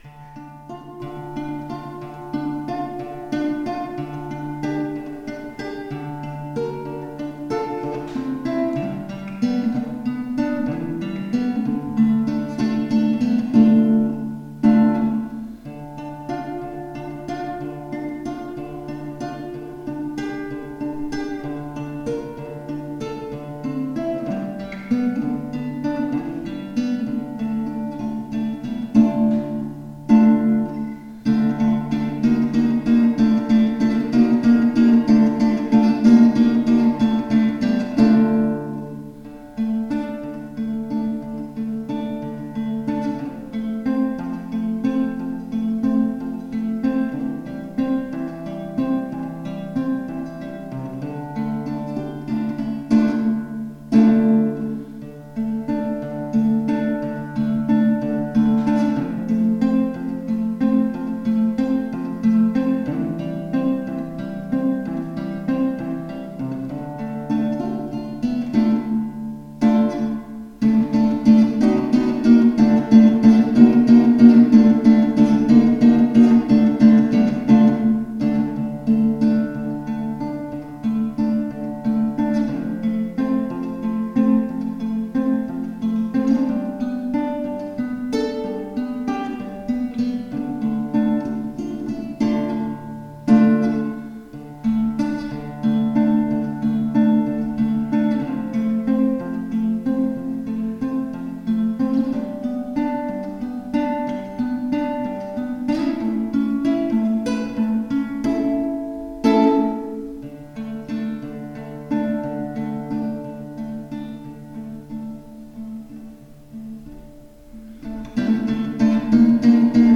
Aufnahmen mit minimalem Equipment
Weils Wetter heute so schön war mal wieder eine Minimalaufnahme von mir, 200€ Pc, Audacity, 12€ Mikro und 100€ HB-Gitarre.